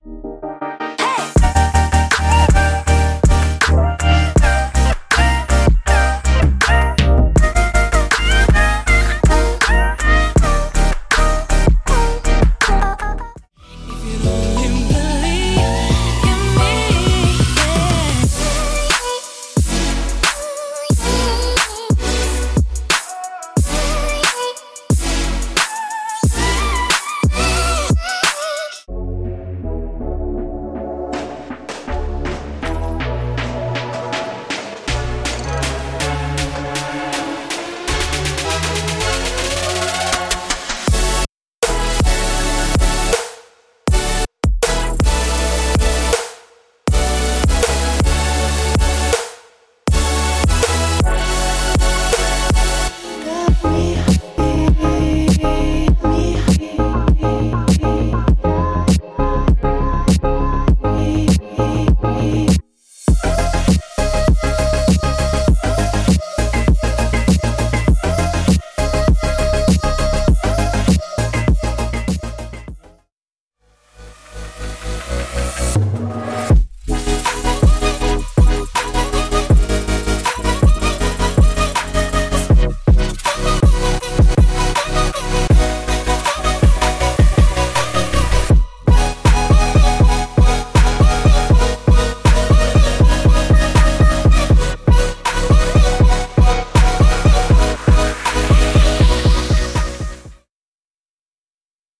Future Bass是当今最相关的舞蹈音乐类型之一。
巨大的超锯，郁郁葱葱的垫子，琶音，弹拨，低音等等。
声音非常适合彼此叠加，您将不需要更多的预设来创建自己梦想的未来低音下降。